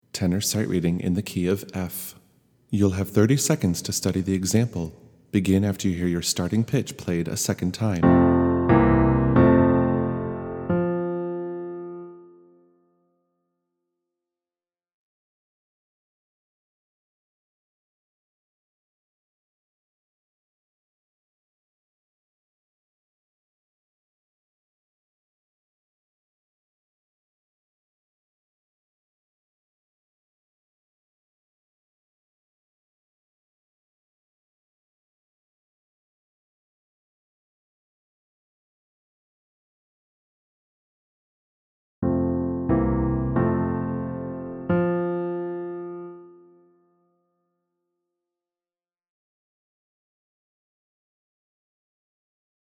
Tenor in F Sight-Reading